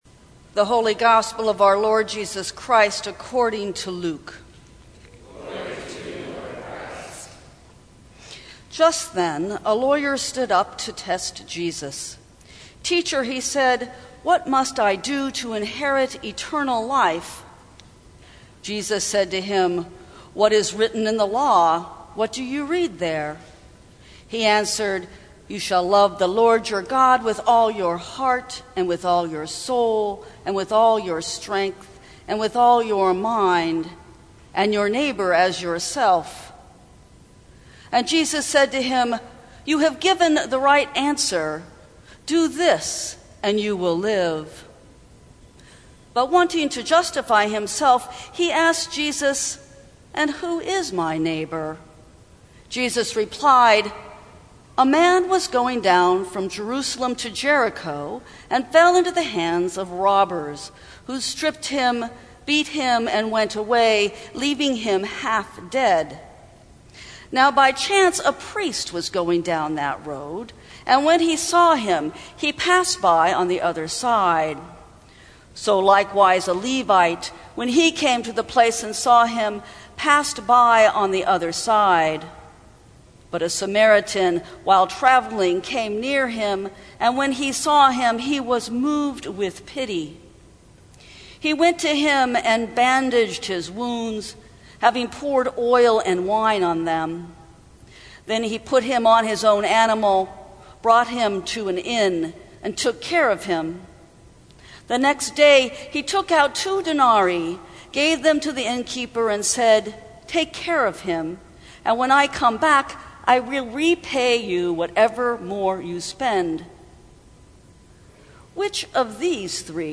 Sermons from St. Cross Episcopal Church Won’t You Be My Neighbor?